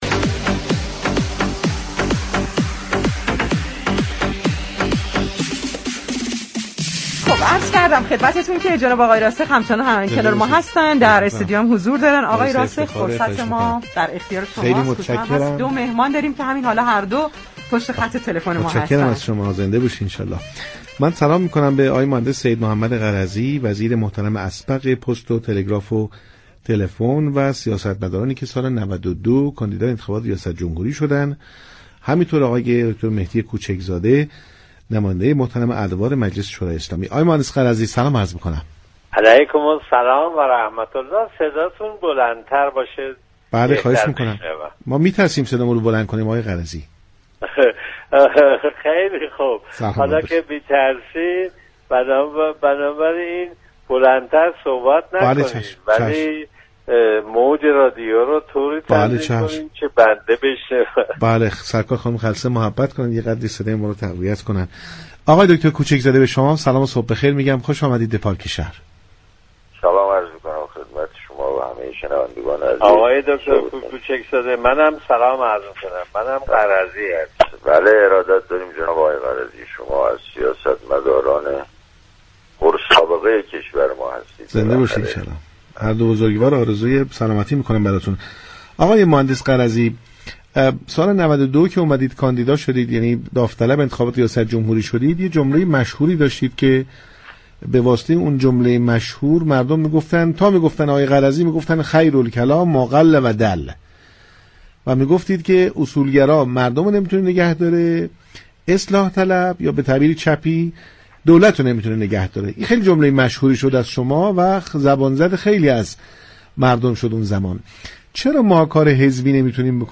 به گزارش پایگاه اطلاع رسانی رادیو تهران، برنامه پارك شهر در راستای گفتگوهای انتخاباتی با سید محمد غرضی سیاستمدار و وزیر پیشین پست، تلگراف و تلفن و نامزد ریاست جمهوری سال 92 و مهدی كوچك زاده نماینده ادوار مختلف مجلس گفتگو كرد.